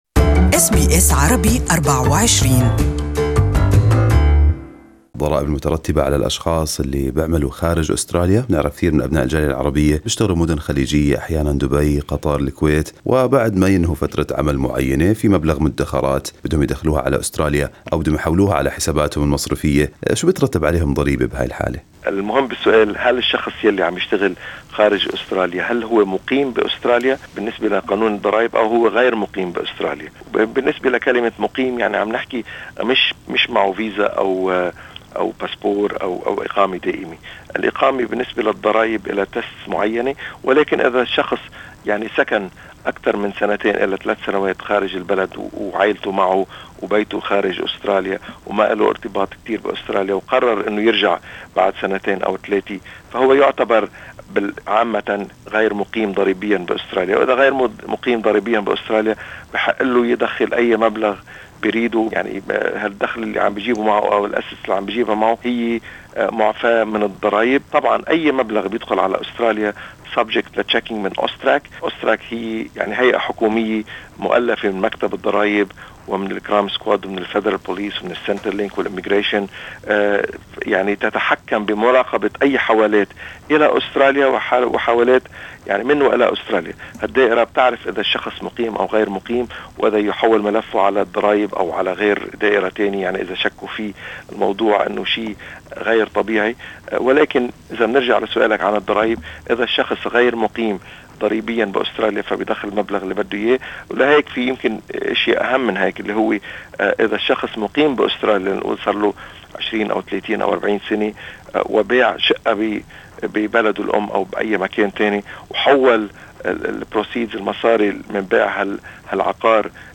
This interview and article are available in Arabic.